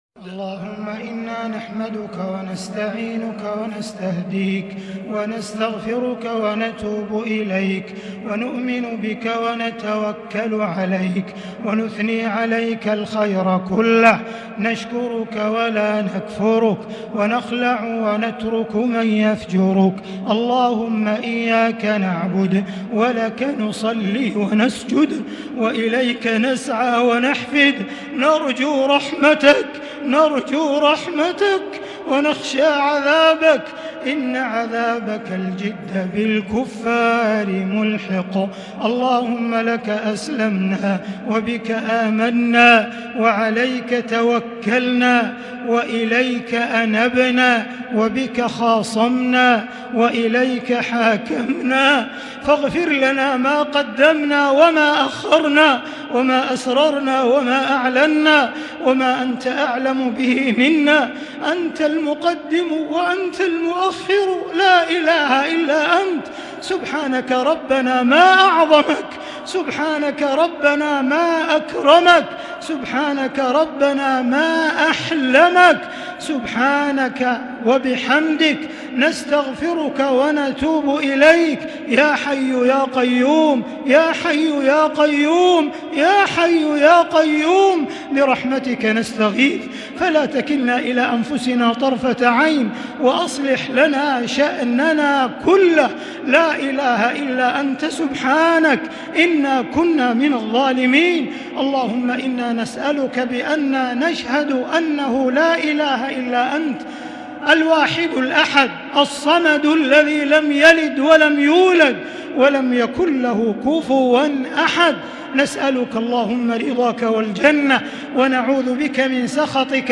دعاء ليلة 13 رمضان 1441هـ > تراويح الحرم المكي عام 1441 🕋 > التراويح - تلاوات الحرمين